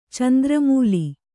♪ candra mūli